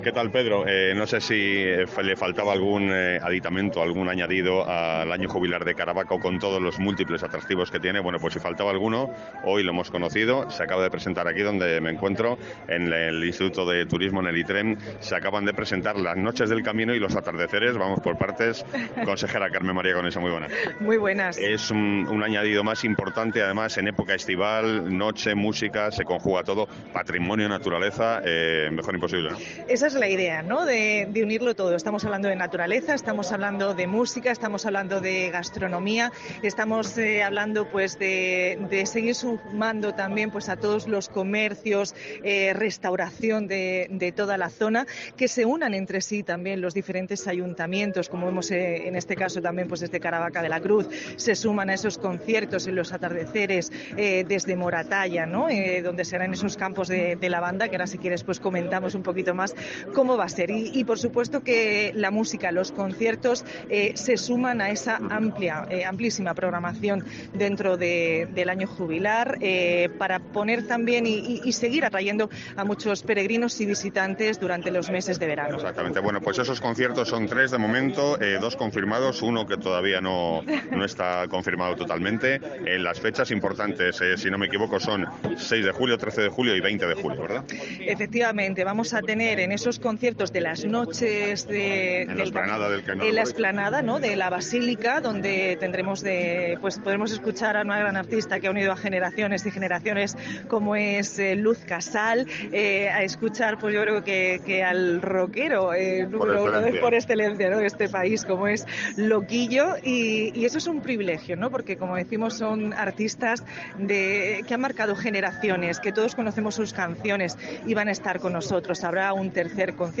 Carmen María Conesa, consejera de Turismo